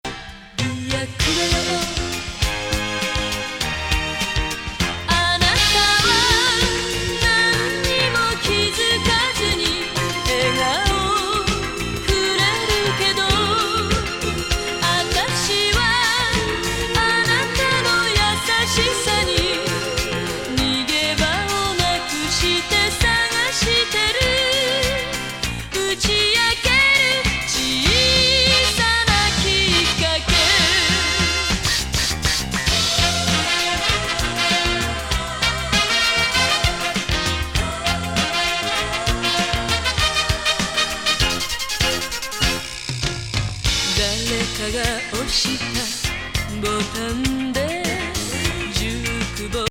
Japanese,Disco,Mellow,Soul ♪試聴ファイル LABEL/PRESS